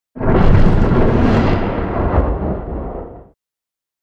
Звук перехода с грозой 19 дек. 2023 г.
Звук перехода для монтажа с грозой thunder